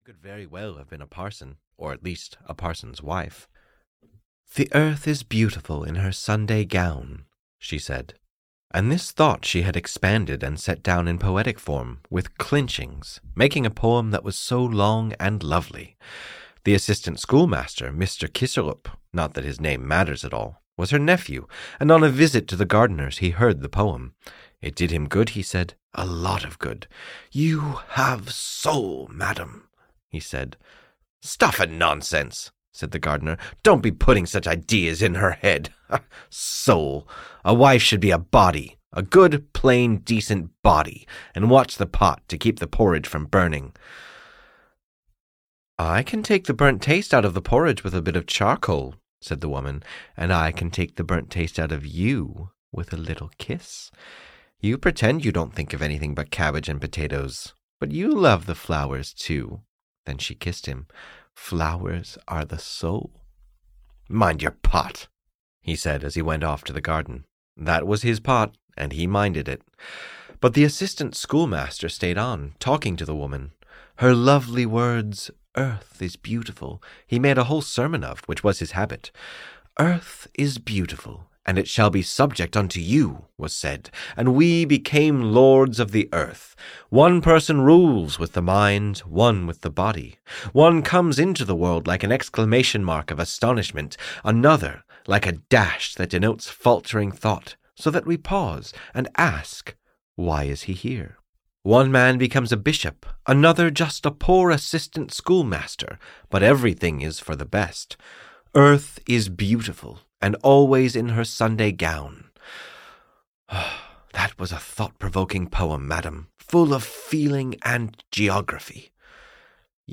The Goblin and the Woman (EN) audiokniha
Ukázka z knihy